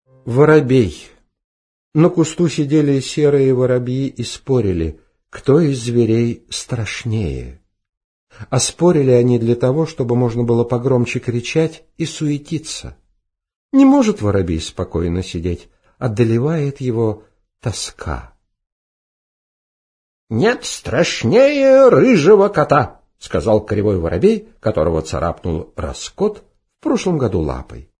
Аудиокнига Воробей